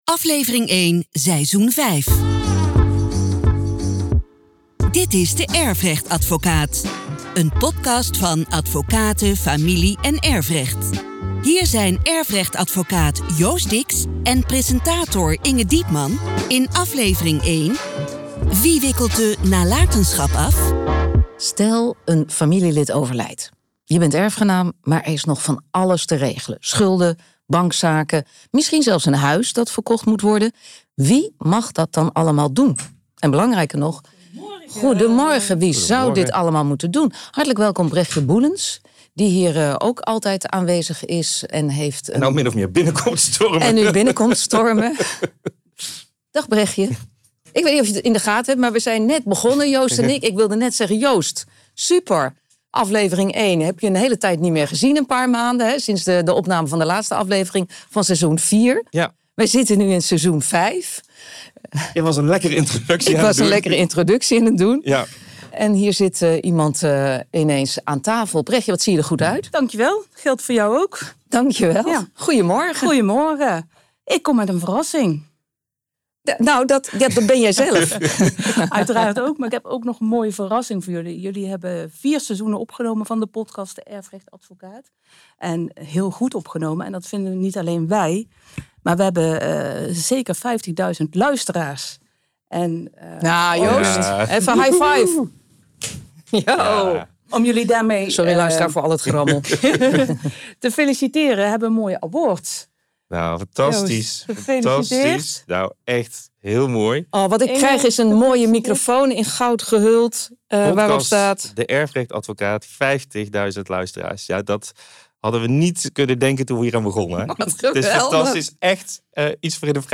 In deze podcastserie ‘De Erfrechtadvocaat’ van Advocaten Familie- & Erfrecht worden de meest gestelde vragen rondom de erfenis behandeld. Presentatrice Inge Diepman